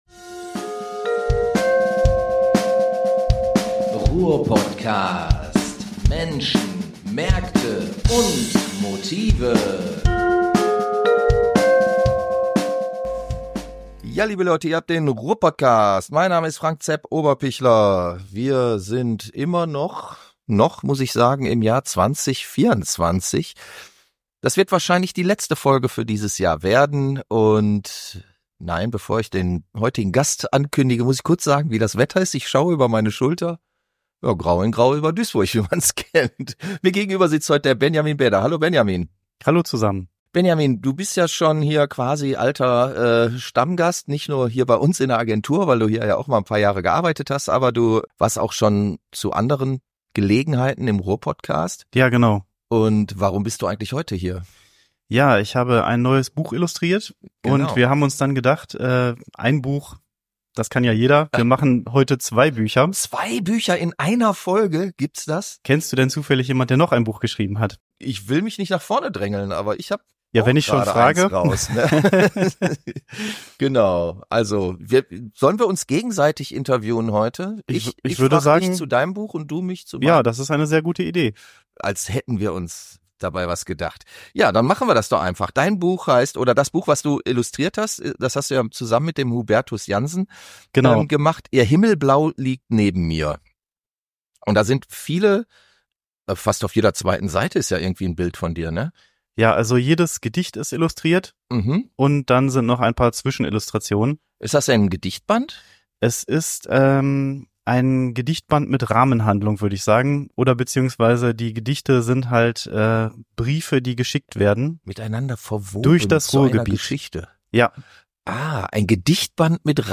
Illustrator wird von Autor interviewt, der daraufhin von Illustrator interviewt wird.